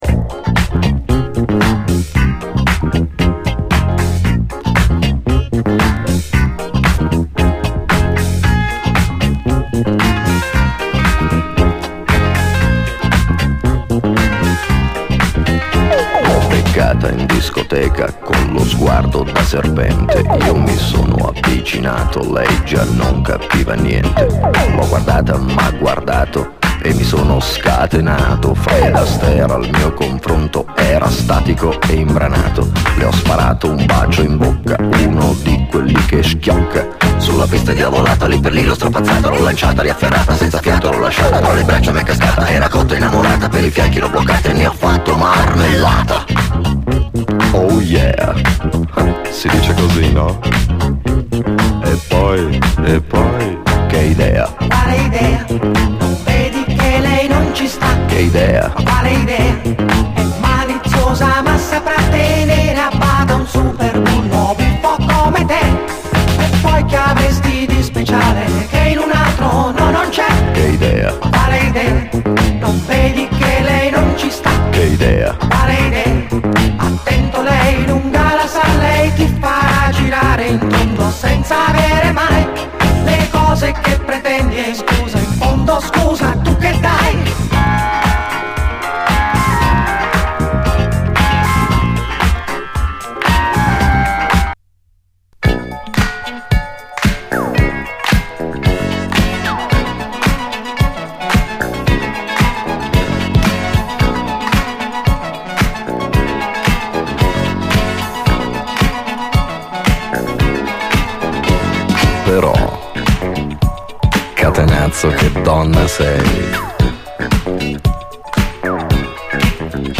SOUL, DISCO, HIPHOP
イタリア産白人ディスコ・ラップ！
ノイズあり